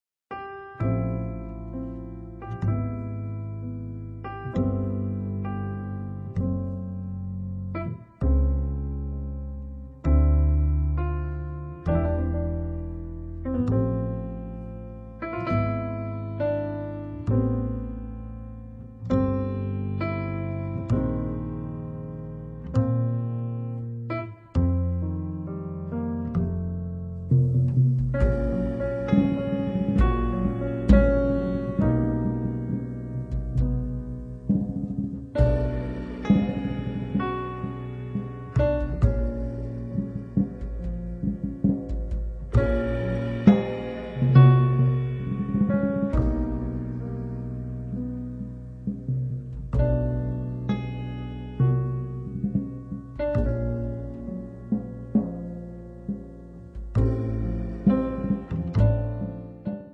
pianoforte
contrabbasso
batteria